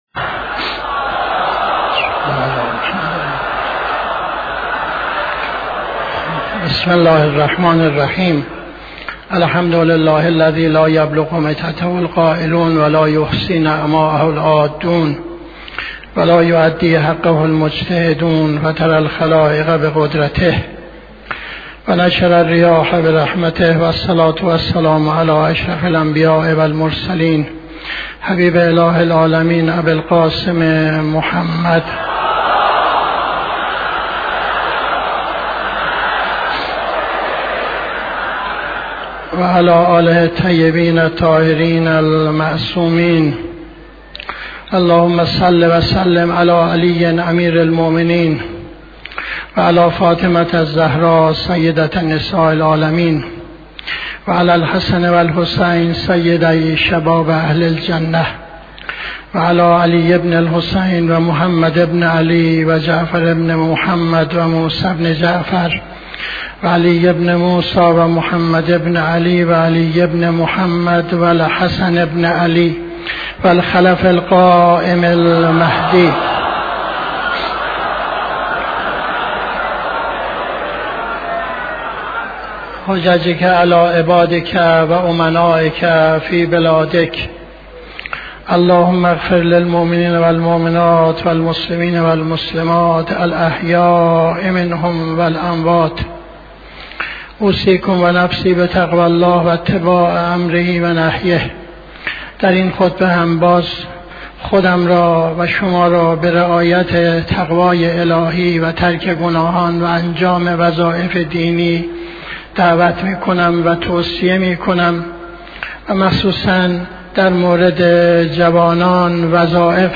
خطبه دوم نماز جمعه 01-11-78